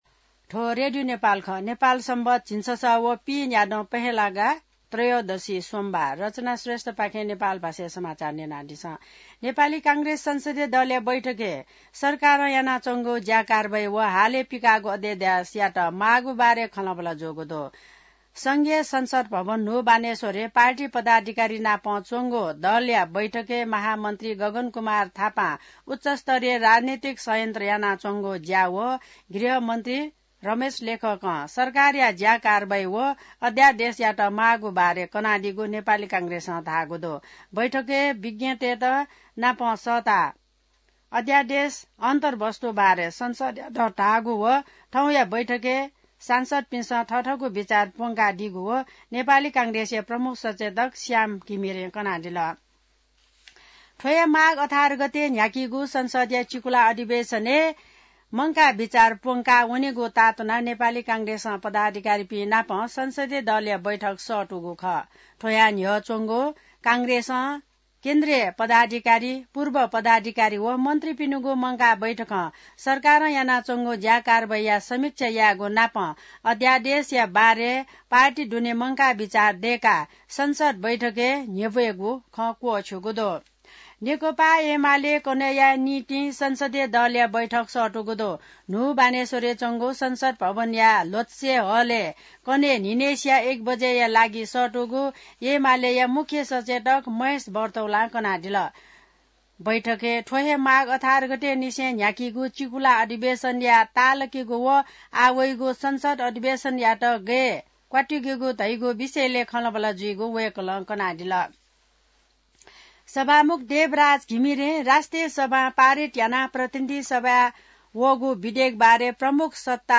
नेपाल भाषामा समाचार : १५ माघ , २०८१